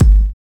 Wu-RZA-Kick 2.wav